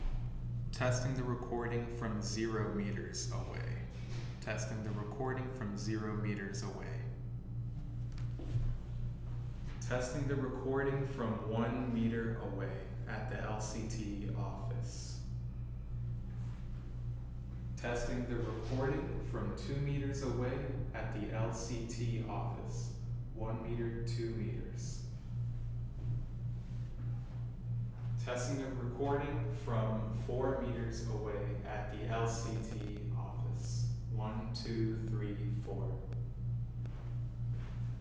Demo 2 – Small Conference Room
Raw recording (Blue Yeti, Condenser Mic):
Audio5_Blue_yeti.wav